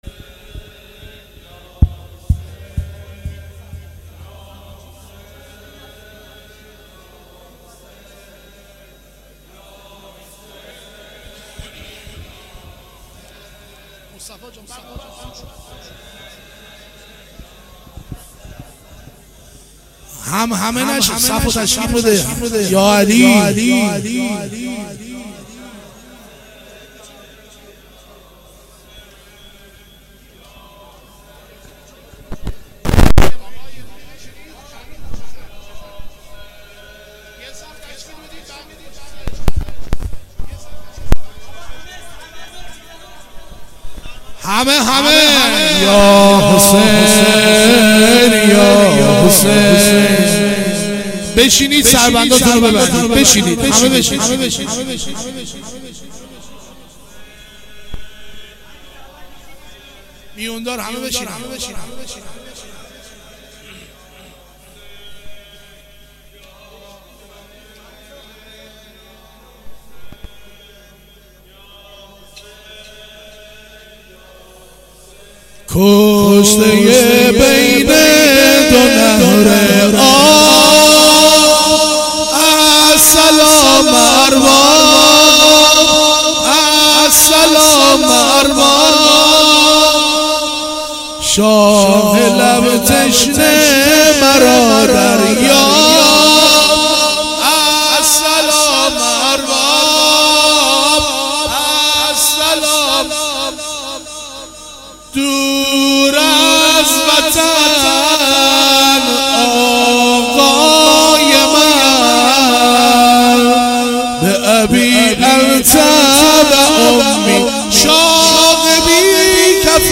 زمینه ه شب دوم محرم 96